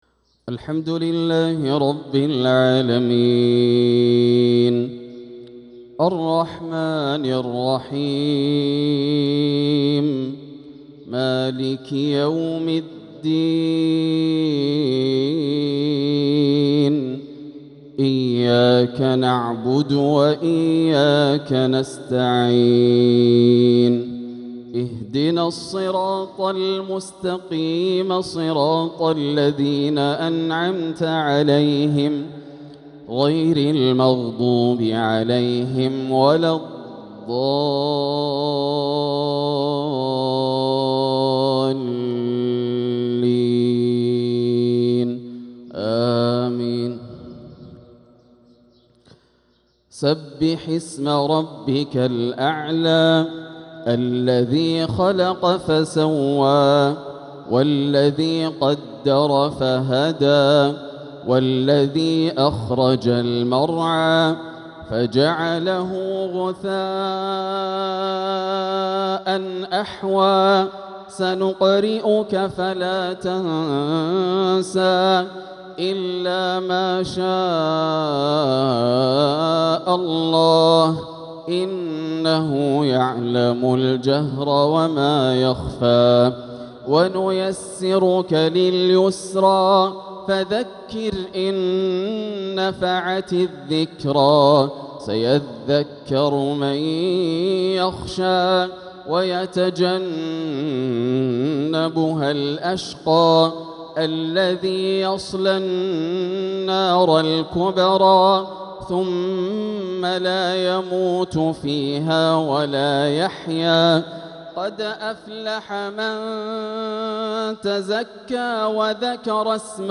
تلاوة لسورتي الأعلى والغاشية | صلاة الجمعة 1-8-1446هـ > عام 1446 > الفروض - تلاوات ياسر الدوسري